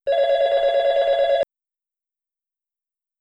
La llamada de línea externa es un ring largo y un silencio,
ring externo.wav